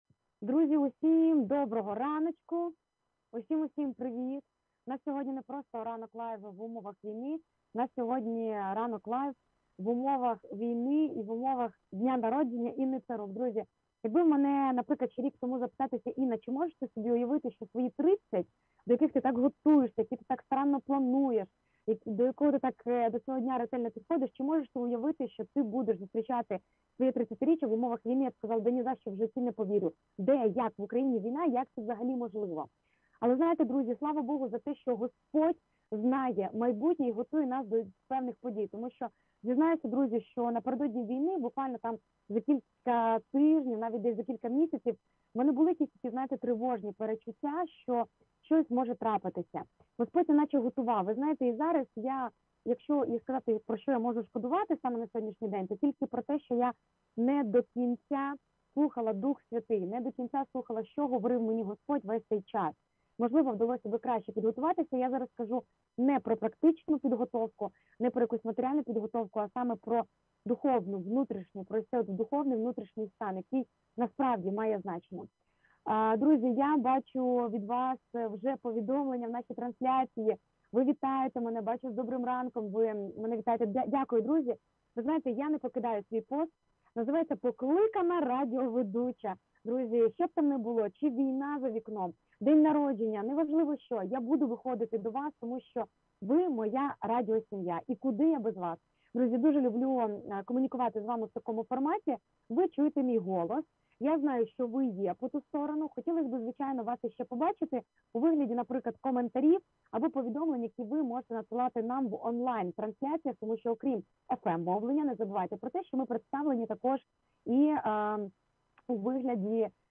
Вони бояться бути самотніми і бути не прийнятими. Про найбільший страх говорили сьогодні в прямому ефірі